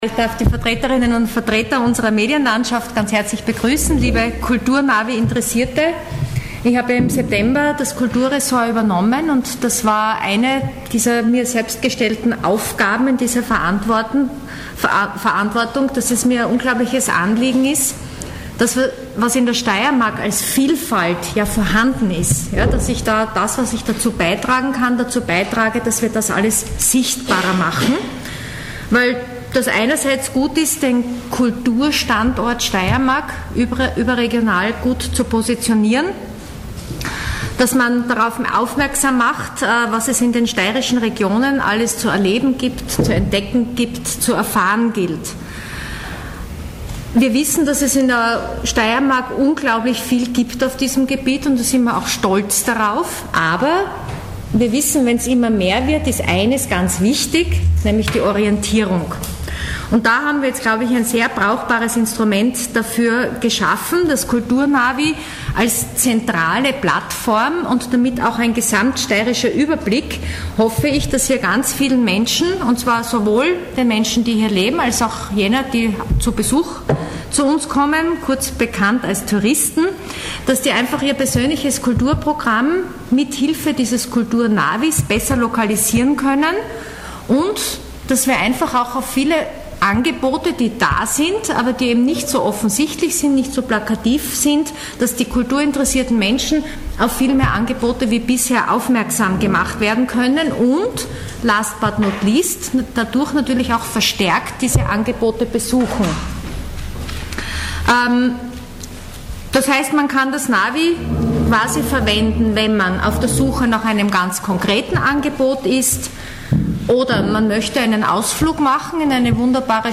O-Ton: Kulturnavi für die Steiermark